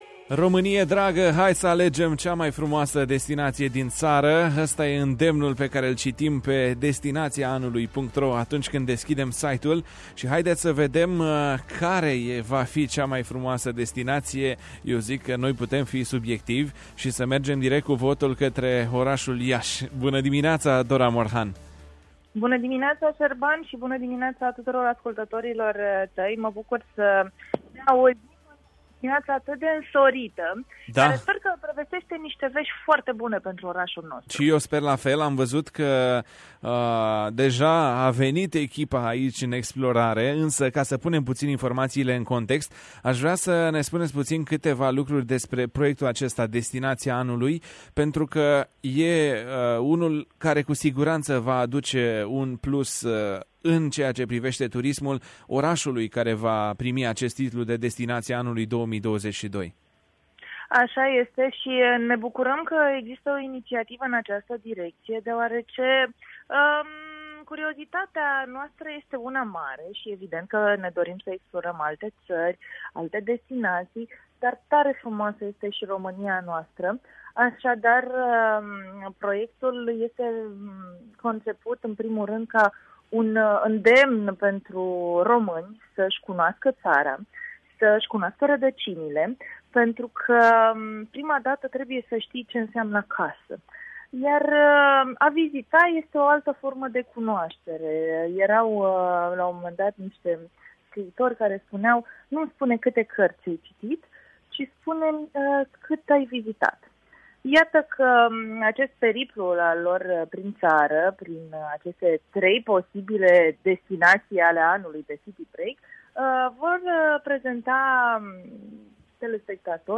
într-un interviu realizat în emisiunea „Bună dimineața”.